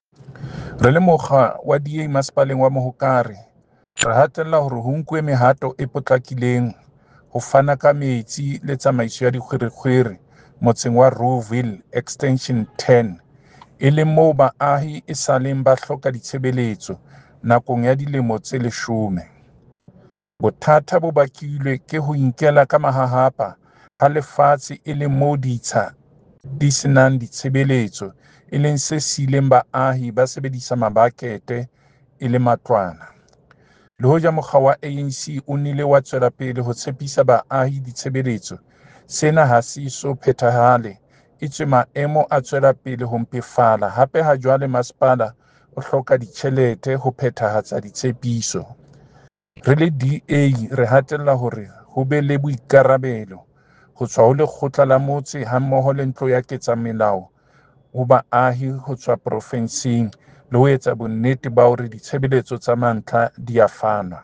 Sesotho soundbite by David Masoeu MPL.
Rouxville-Water-Sesotho.mp3